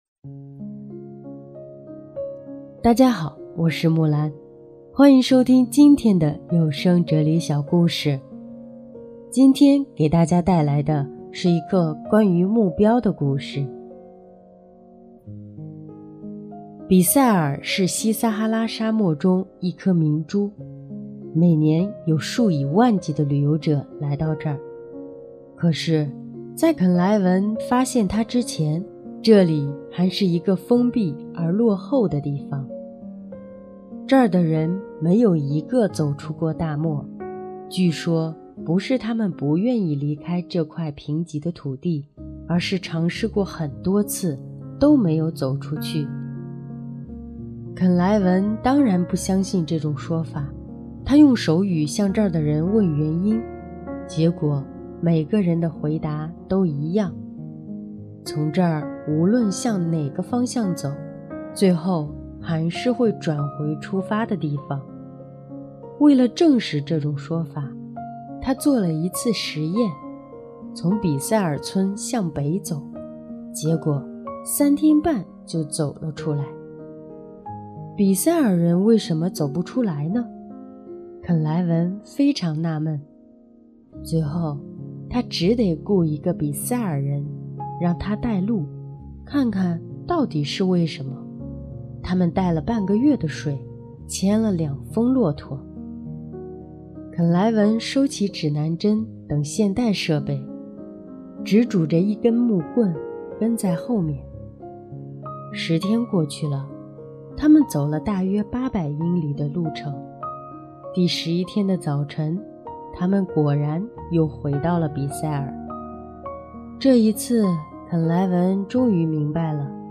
【哲理小故事】|车祸之后